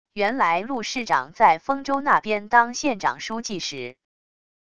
原来陆市长在丰州那边当县长书记时wav音频生成系统WAV Audio Player